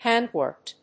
アクセント・音節hánd・wórked